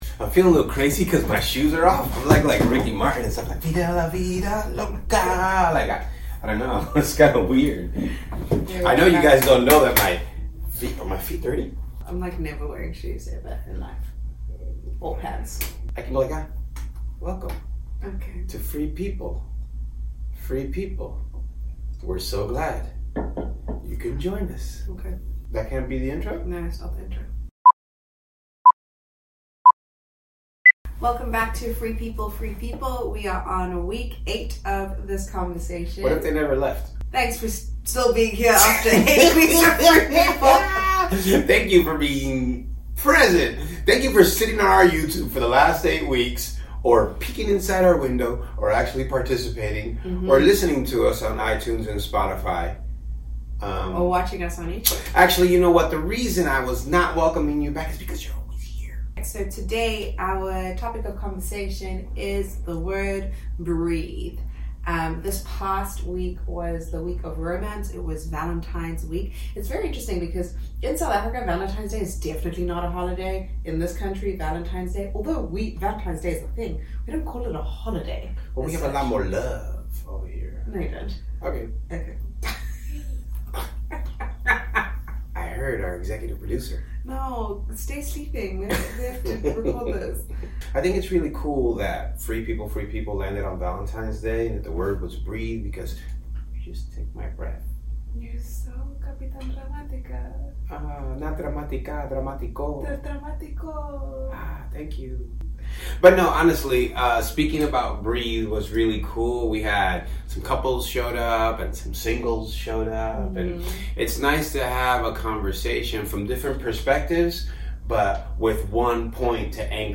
In todays conversation we tackle anxiety, and creating margins in our life to have more breathing room.